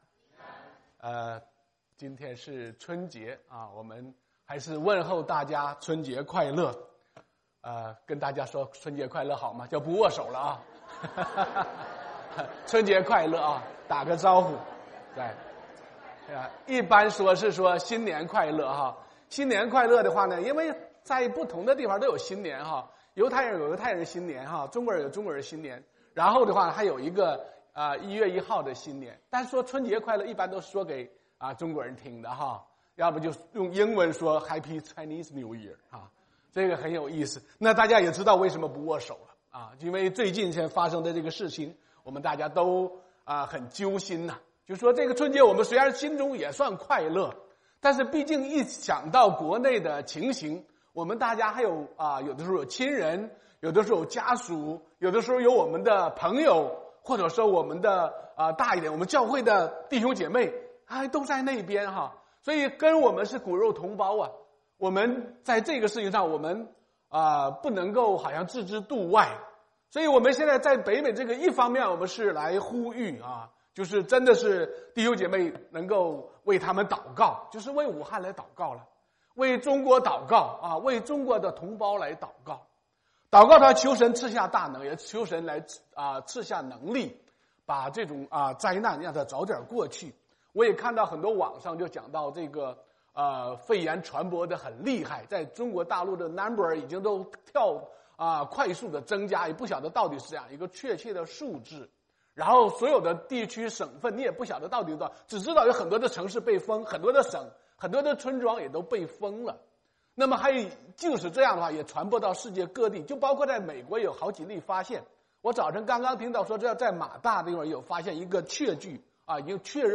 中文講道 | 基督教華府中國教會